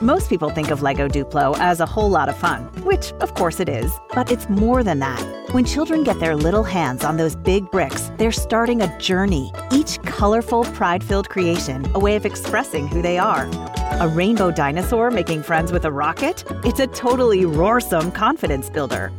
Lego Duplo ad